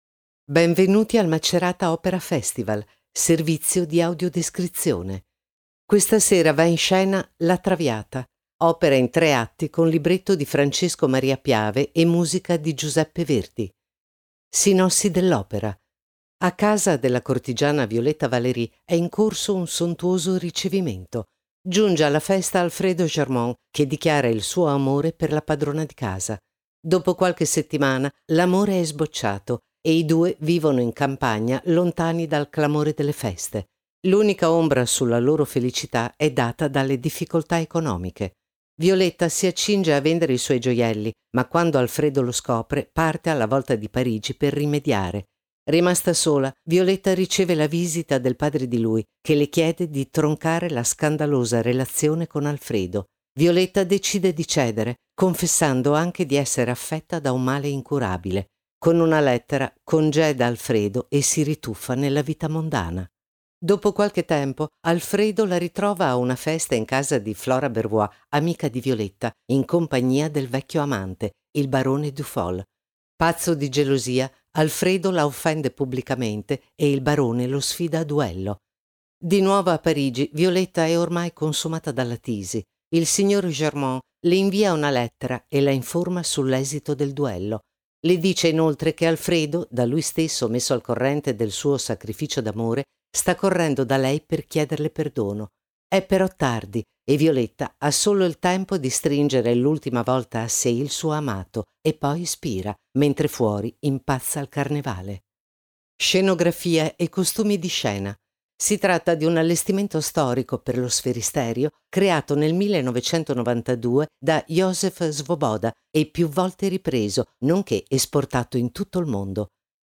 Audio introduzioni